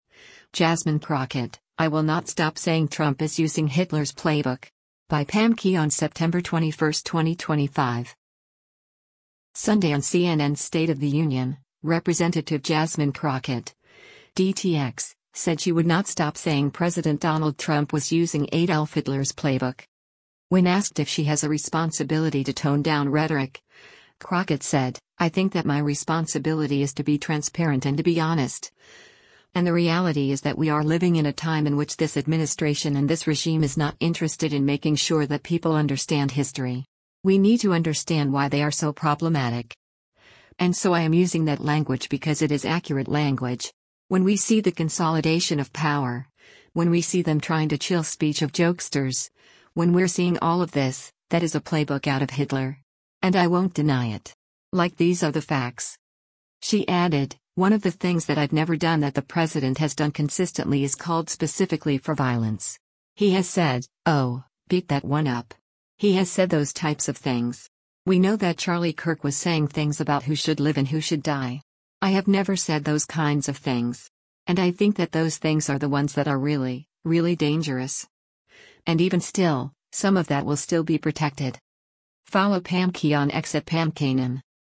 Sunday on CNN’s “State of the Union,” Rep. Jasmine Crockett (D-TX) said she would not stop saying President Donald Trump was using Adolf Hitler’s playbook.